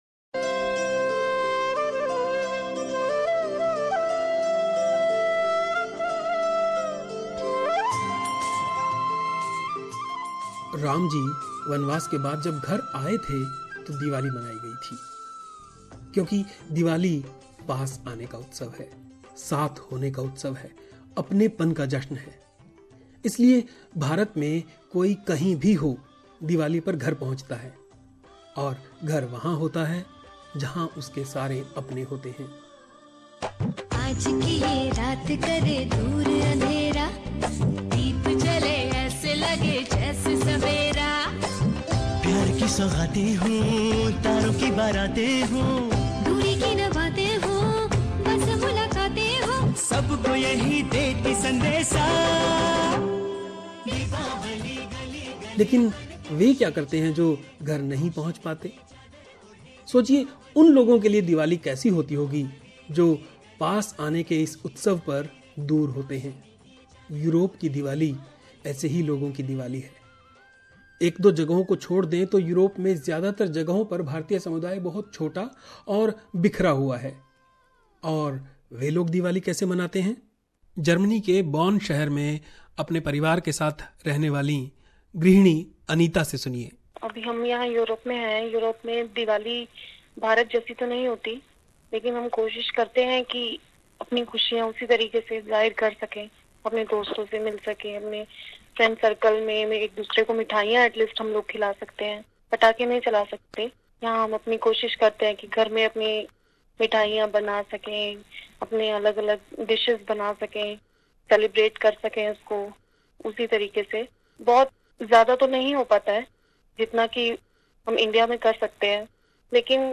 रिपोर्ट बॉन जर्मनी से